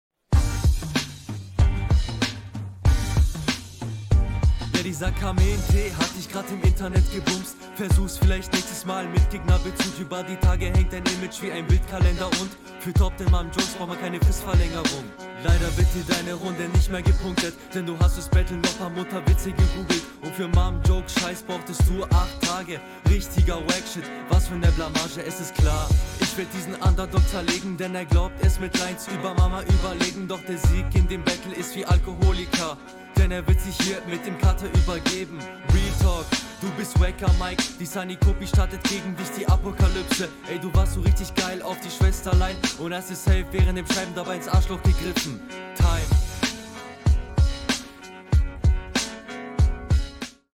Klingt leider noch sehr amateurhaft, aber bleib dran, dann wird das mit der Zeit.
Flowlich auf jeden Fall direkt schwächer. Klingt einfach alles unsicherer.